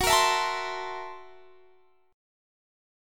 Listen to Gb7#9b5 strummed